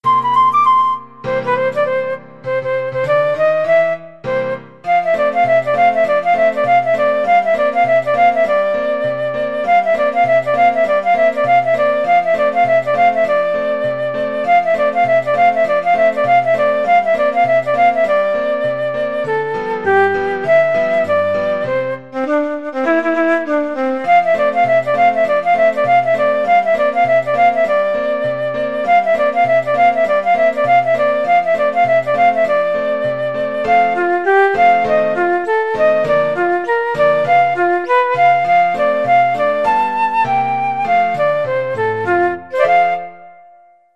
Arrangement for Flute and Piano
4/4 (View more 4/4 Music)
Jazz (View more Jazz Flute Music)
Ragtime Music for Flute